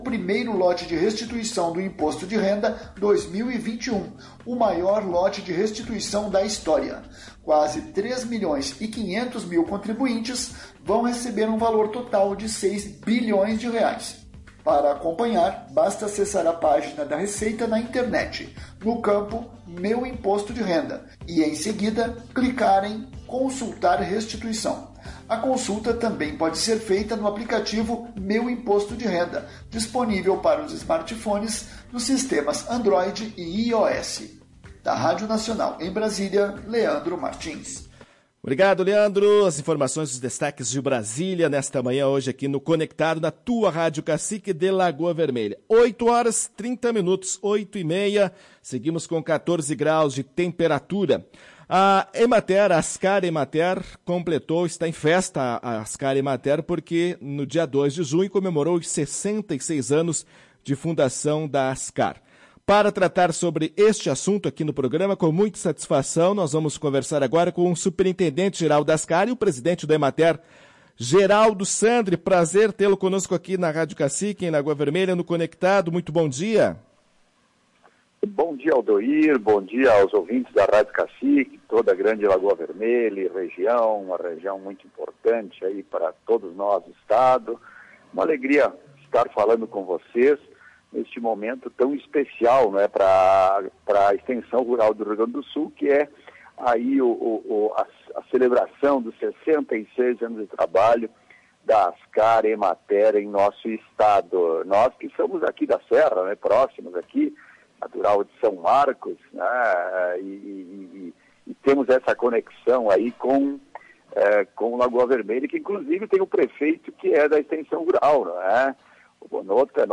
Em entrevista à Tua Rádio Cacique, na manhã desta segunda-feira (07), o presidente da Emater/RS – Ascar, Geraldo Sandri falou sobre a comemoração dos 66 anos de fundação da entidade e andamento da alguns projetos. Entre eles, está a realização de um concurso público para o próximo semestre.